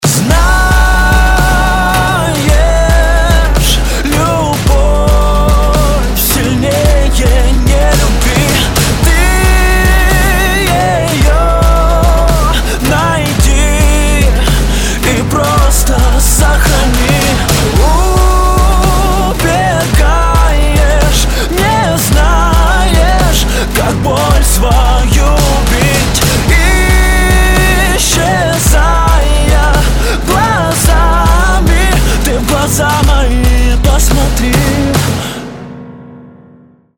• Качество: 192, Stereo
поп
мужской вокал
Романтическая композиция от украинского сердцееда